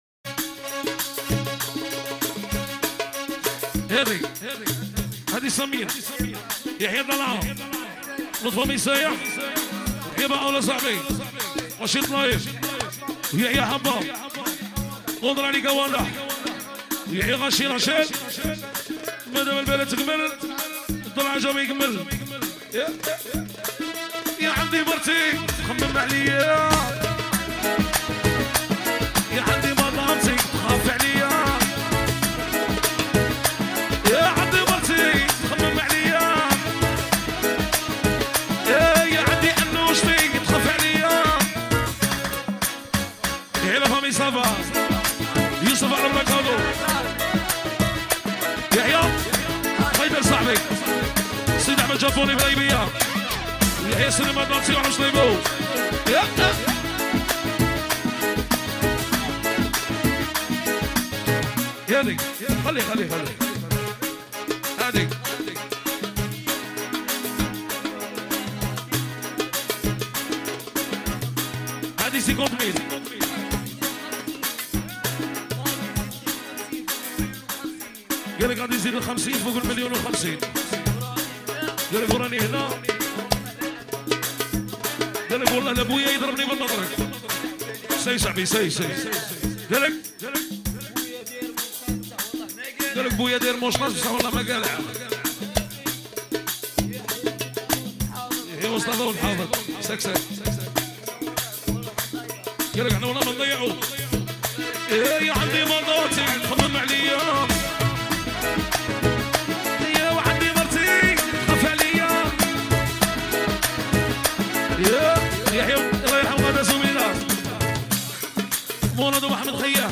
" اغاني راي جزائري "